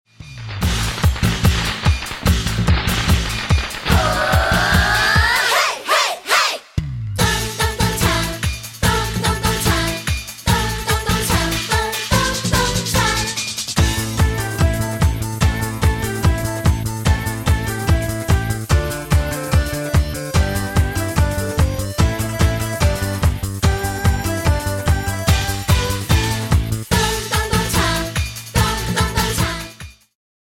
充滿動感和時代感
有伴奏音樂版本
伴奏音樂